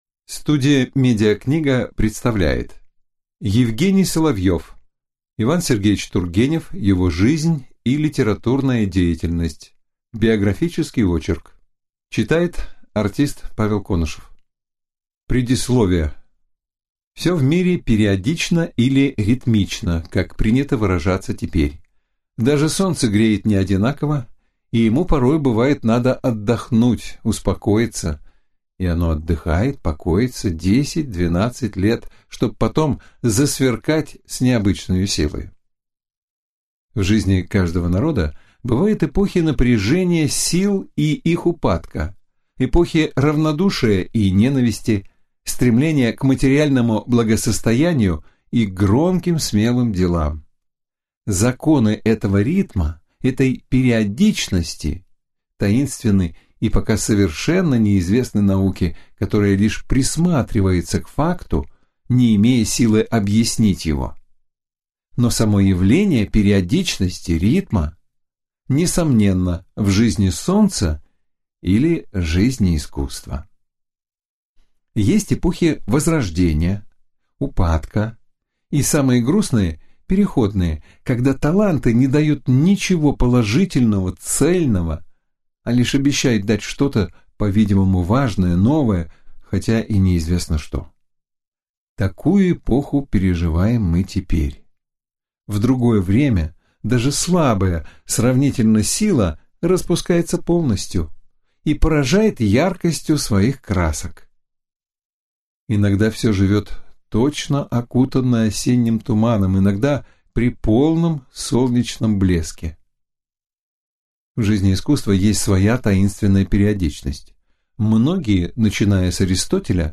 Аудиокнига И. С.Тургенев. Его жизнь и литературная деятельность | Библиотека аудиокниг